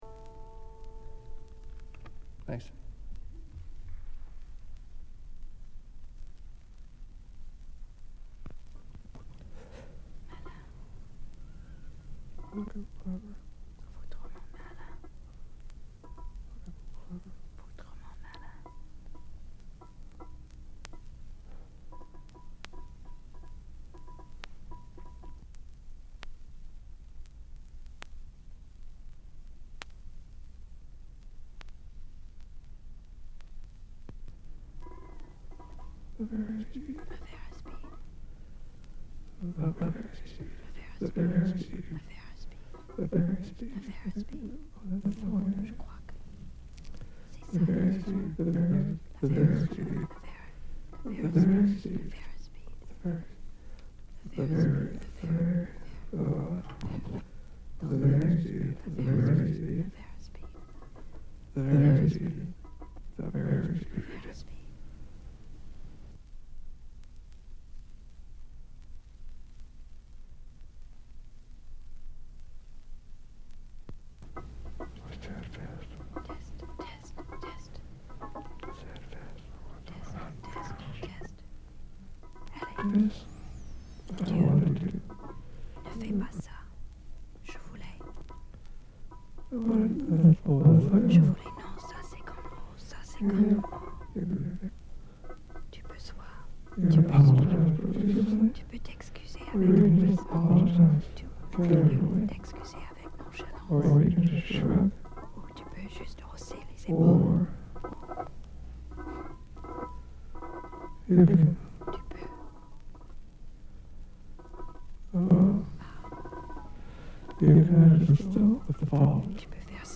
poesia
Recorded Mills College, Oakland, CA, May 14, 1978